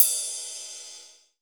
RIDE CS3  -R.WAV